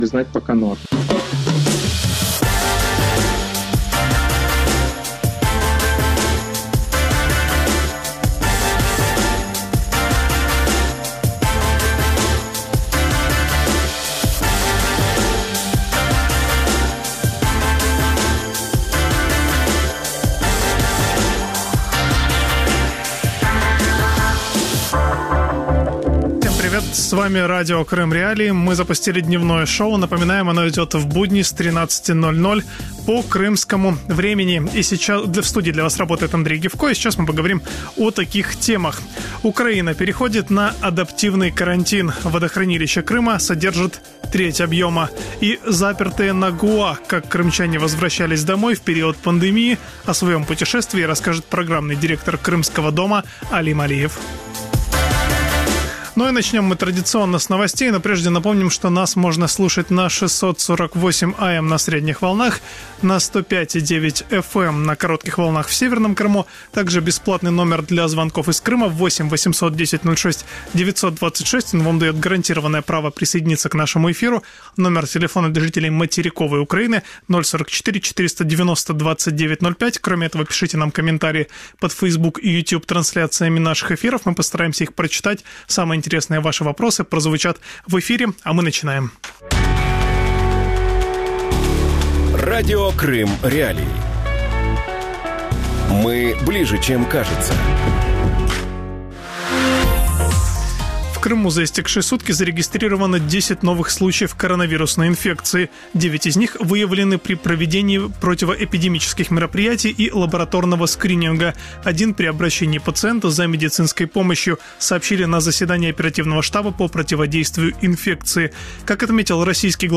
Запертые на Гоа. Как крымчане возвращались домой в период пандемии | Дневное ток-шоу